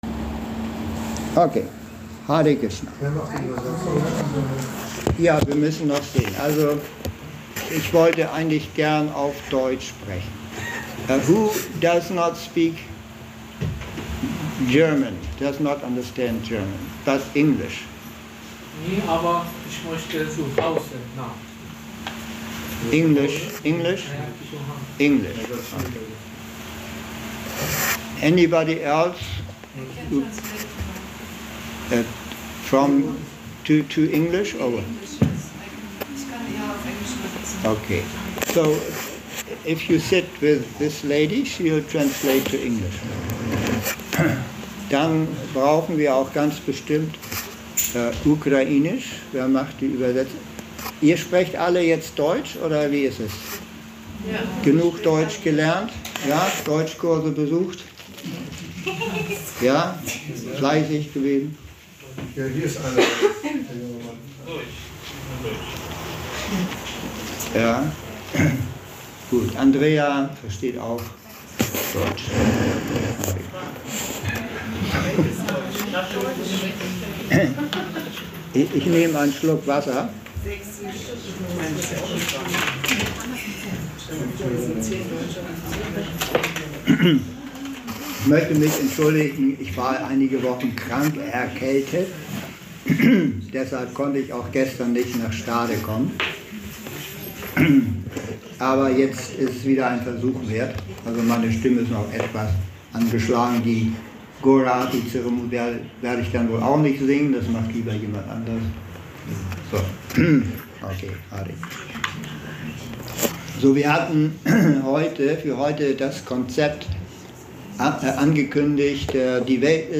Die ganze Welt ist eine Familie - Vortrag zu Bhagavad Gita 14.4
Vorträge im Bhakti Yoga Zentrum Hamburg